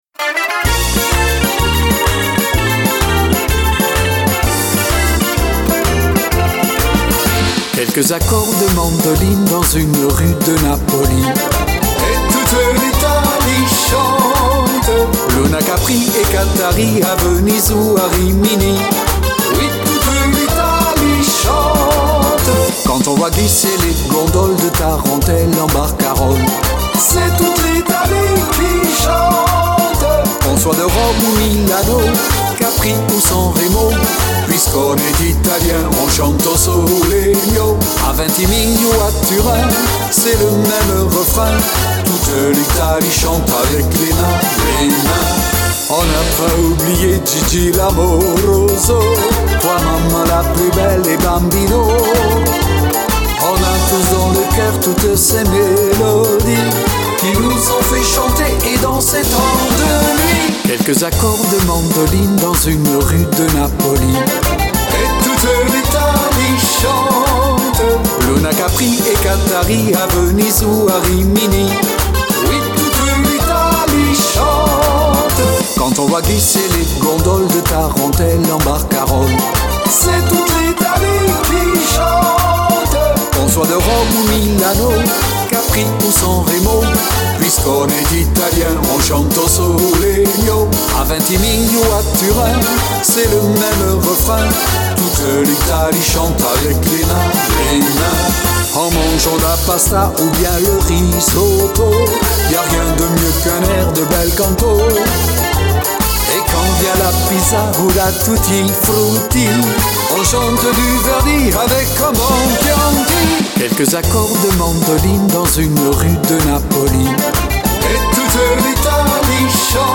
version chantée intégrale